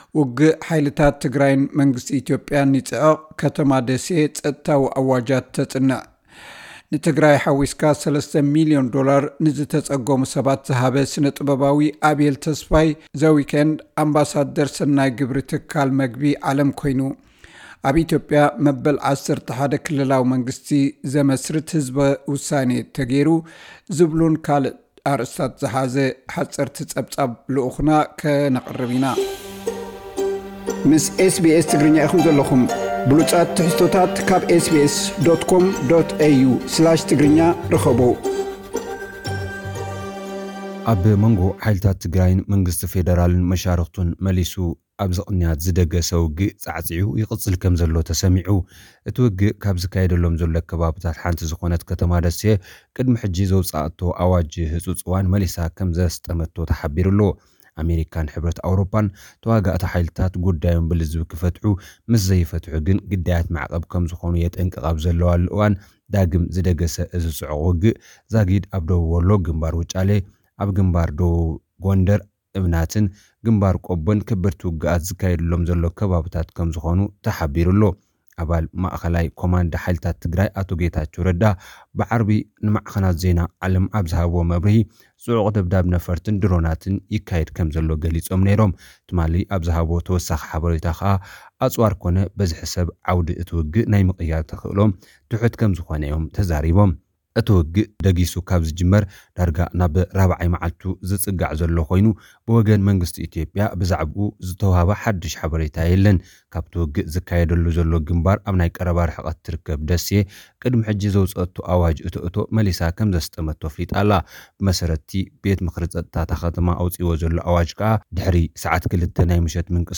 ውግእ ሓይልታት ትግራይን መንግስቲ ኢትዮጵያን ይጽዕቕ ፤ ከተማ ደሴ ጸጥታዊ ኣዋጃት ተስጥም። (ጸብጻብ)